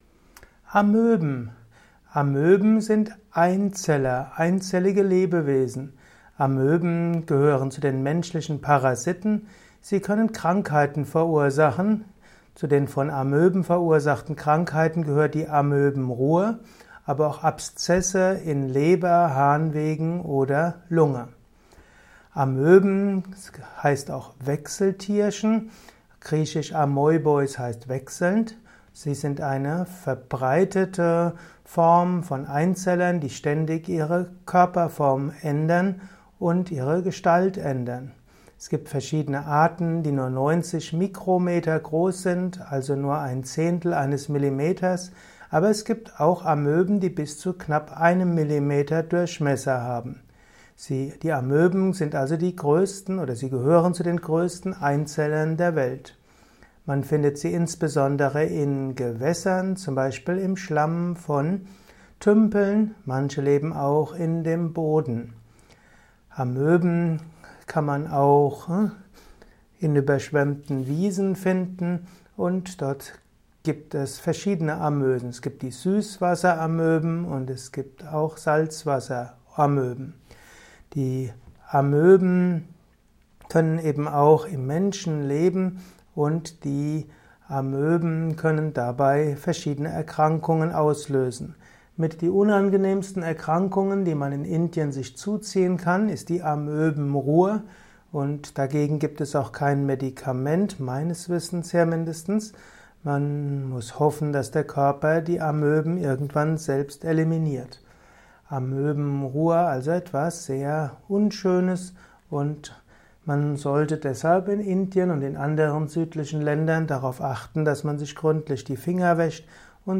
Simple und kompakte Infos zu Amöben in diesem Kurzvortrag